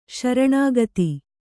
♪ śaraṇāgati